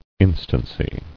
[in·stan·cy]